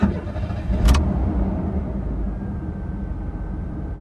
CosmicRageSounds / ogg / general / cars / start.ogg